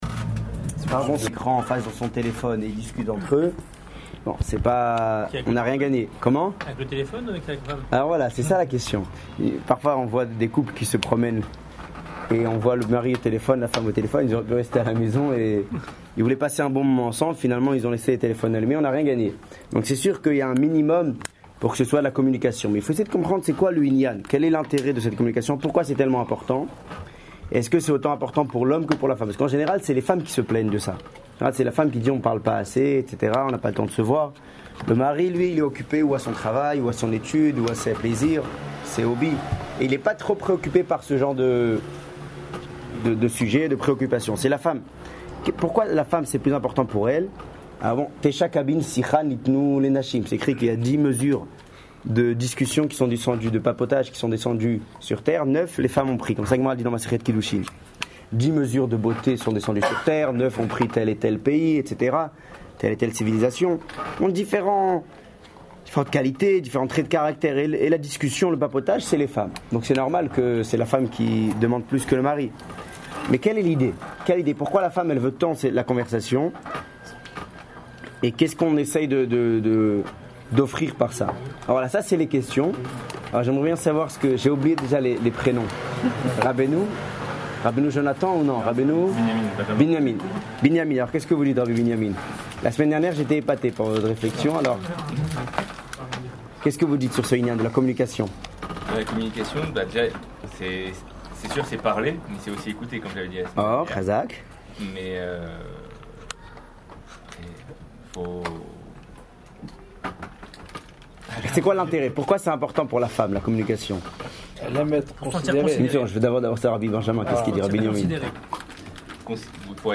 Cours sur le Chalom Baït N°2